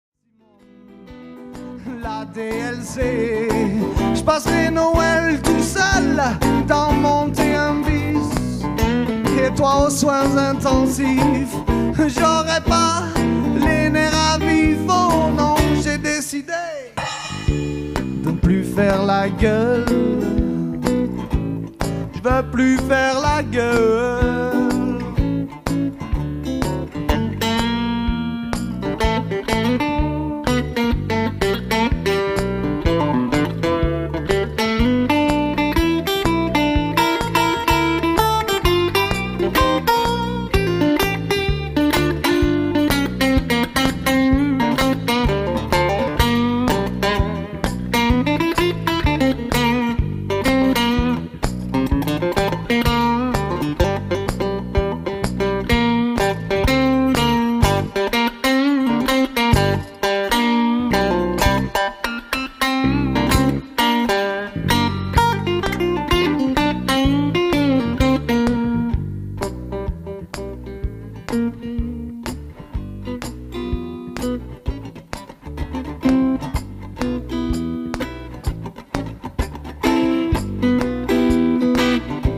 Enregistré en concert vivant, le 10/02/2001.
dans un style musical connoté blues.